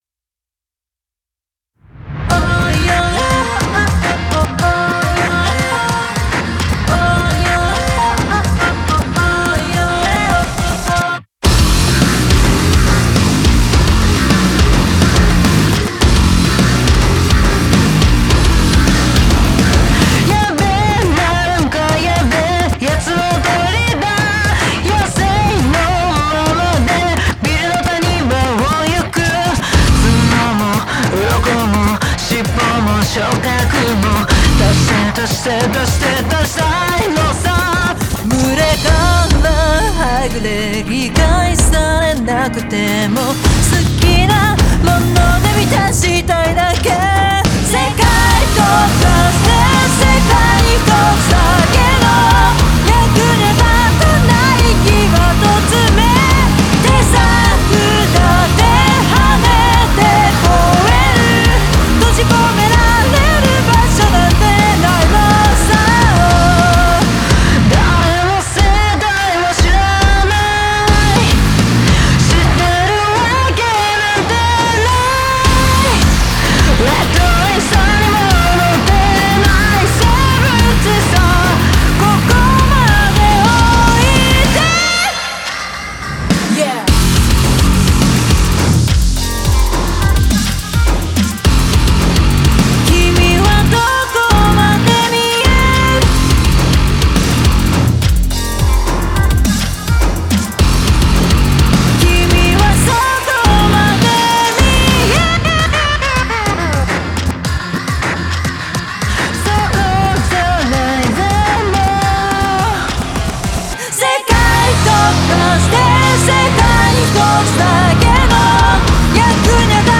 BPM105
Audio QualityPerfect (High Quality)